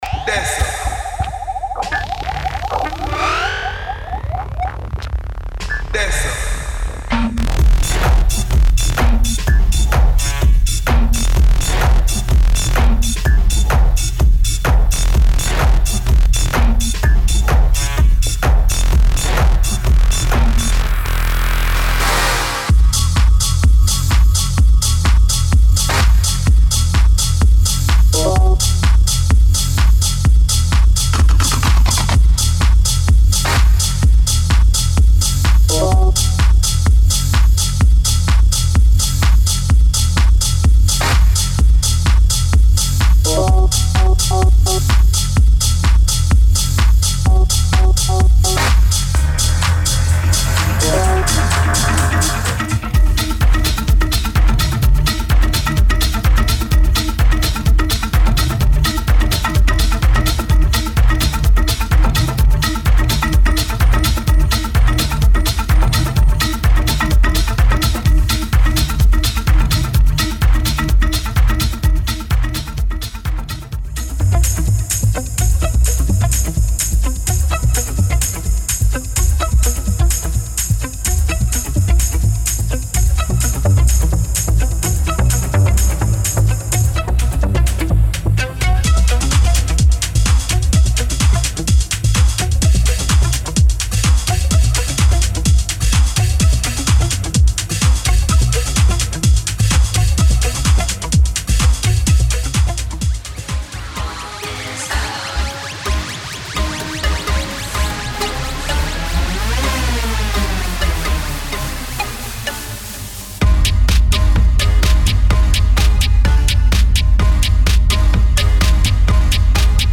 デモサウンドはコチラ↓
Genre:Techno